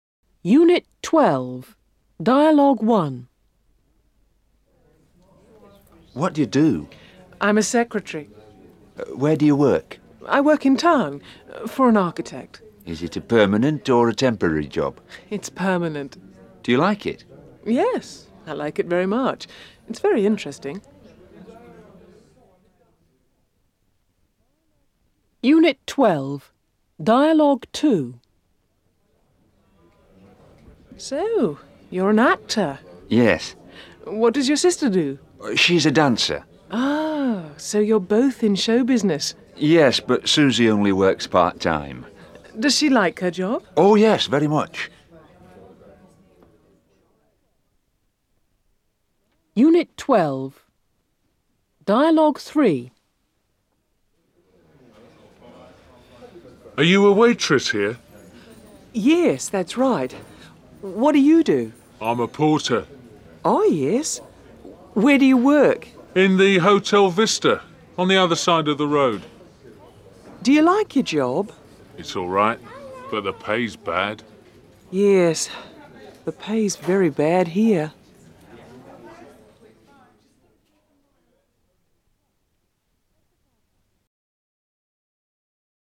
03 - Unit 12, Dialogues.mp3